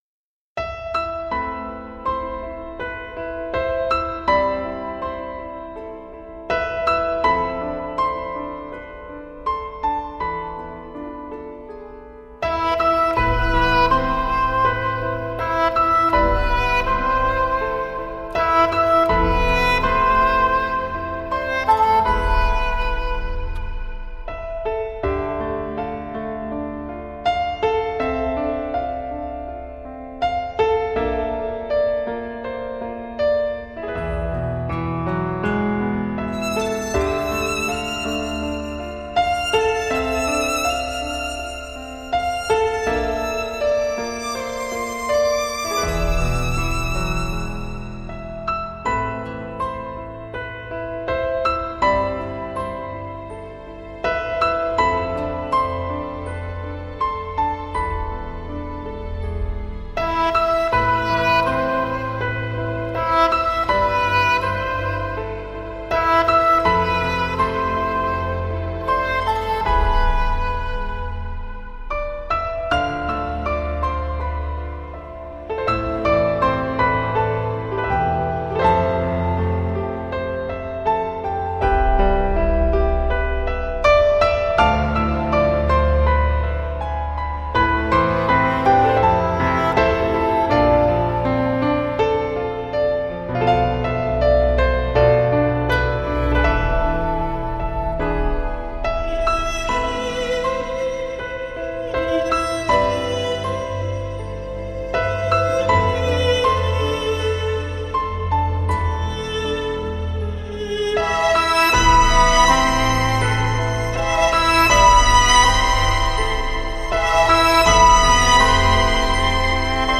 空灵飘渺的音乐世界